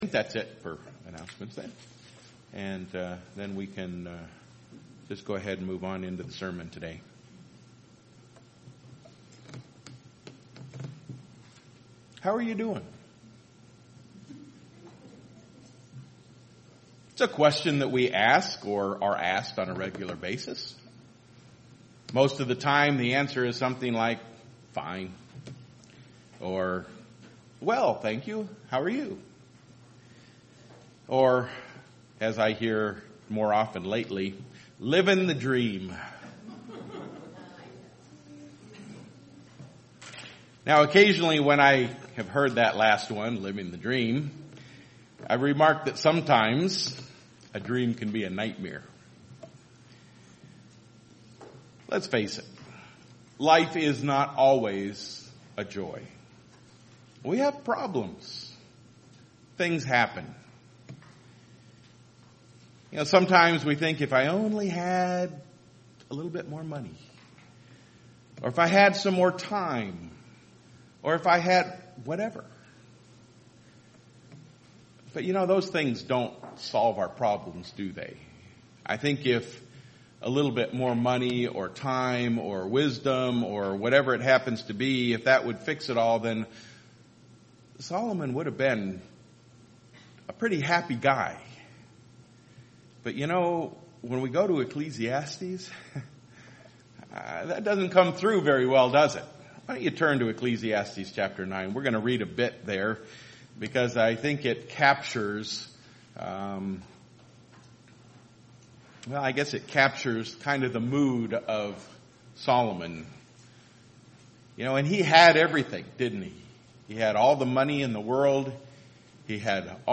Sermons
Given in Freeland, MI